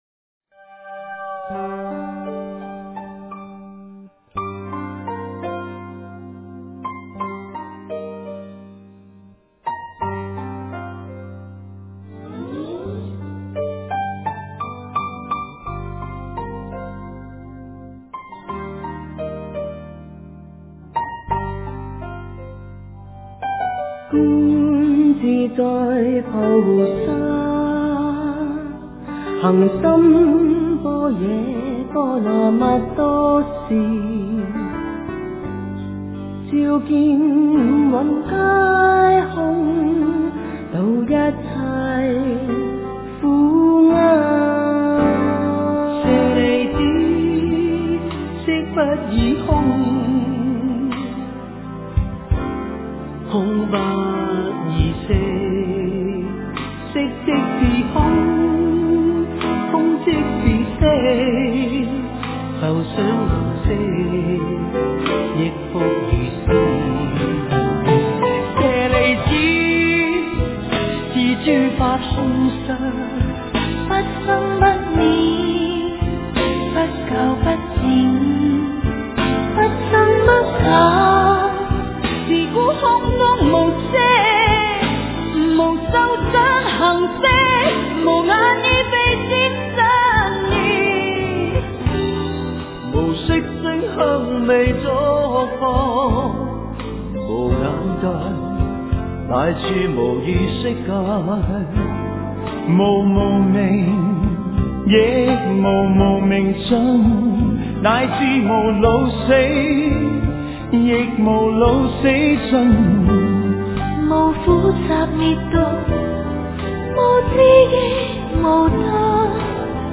心经 诵经 心经--莫少聪 点我： 标签: 佛音 诵经 佛教音乐 返回列表 上一篇： 心经 下一篇： 般若波罗蜜多心经 相关文章 莲花处处开--唱经给你听 莲花处处开--唱经给你听...